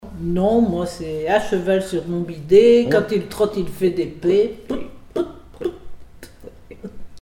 formulette enfantine : sauteuse
Comptines et formulettes enfantines